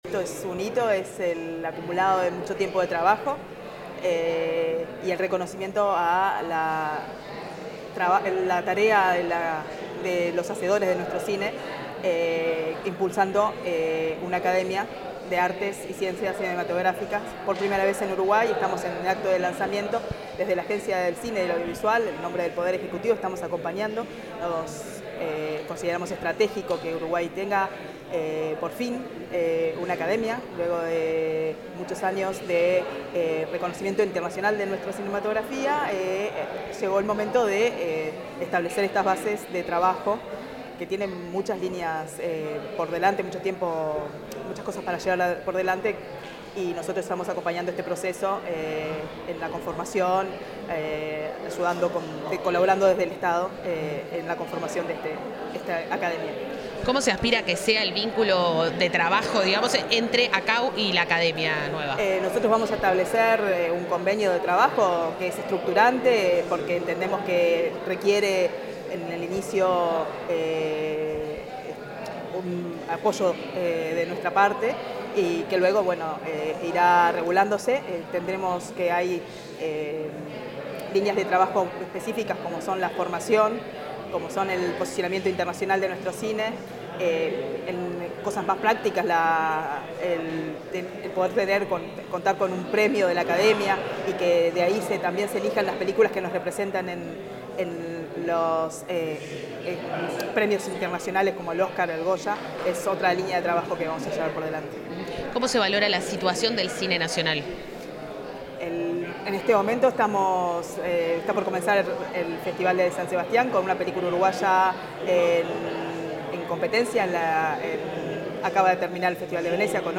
Declaraciones de la presidenta de ACAU, Gisella Previtali